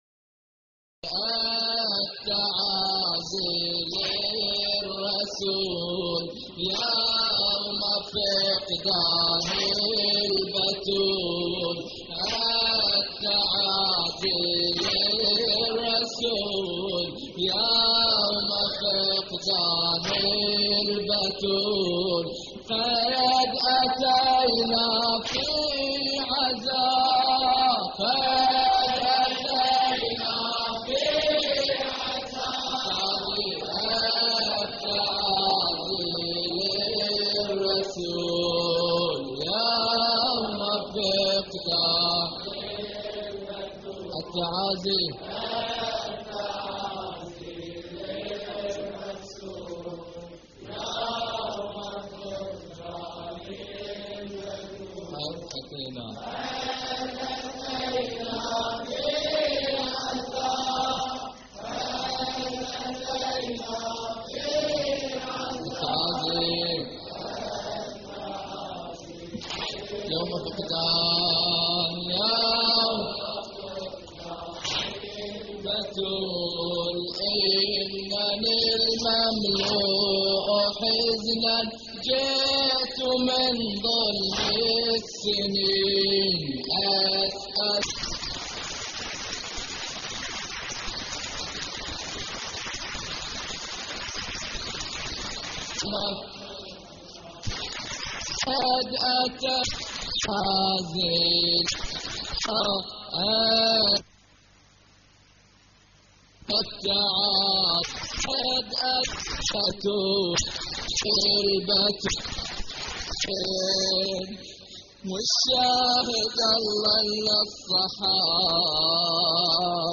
تحميل : التعازي للرسول يوم فقدان البتول / مجموعة من الرواديد / اللطميات الحسينية / موقع يا حسين